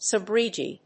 subrogee.mp3